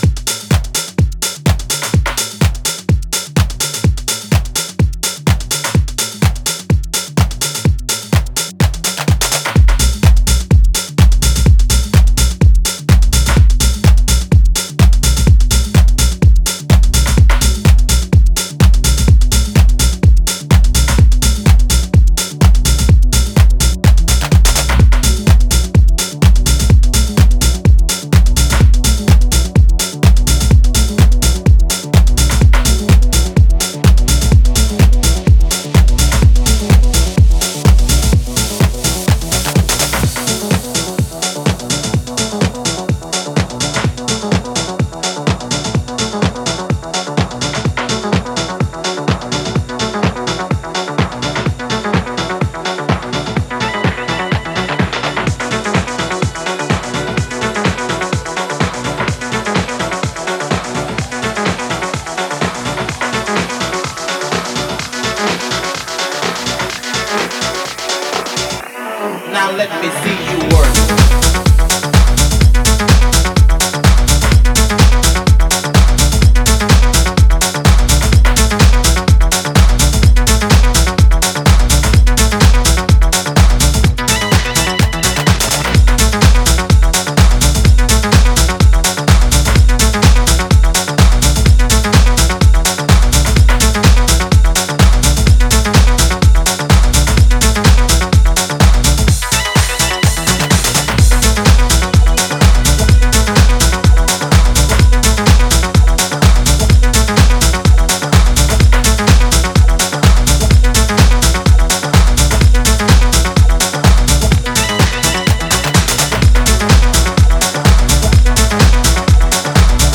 定番的な楽曲からレアブギーまでをDJユースに捌いた